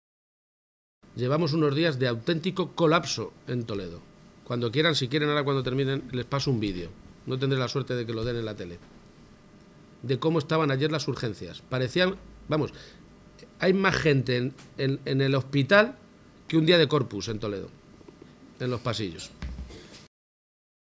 García-Page se pronunciaba de esta manera esta mañana, en Talavera de la Reina, tras reunirse con la Junta Directiva de la Federación Empresarial Talaverana (FEPEMPTA), en la ciudad de la cerámica.